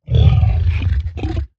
sounds / mob / zoglin / idle2.ogg